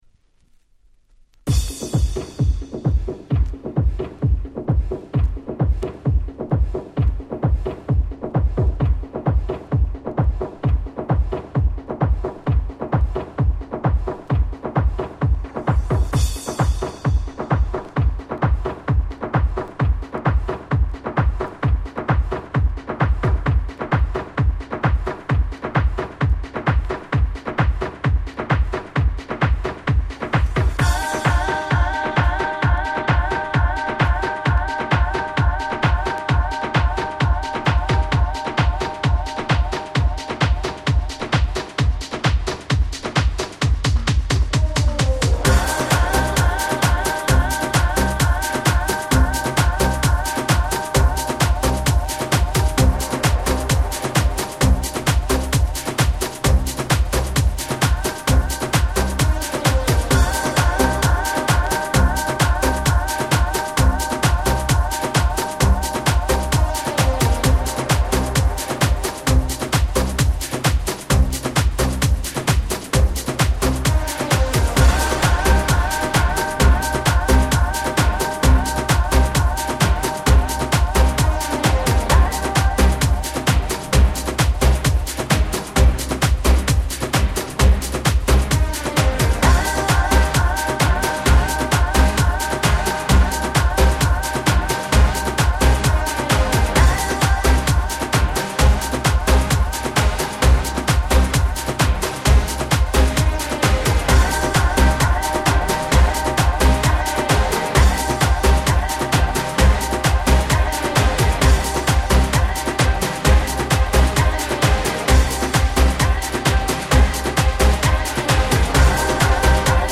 世界的大ヒットR&B♪